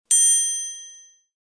Звуки подмигивания
Звук подмигивания одним глазом как в мультфильме динг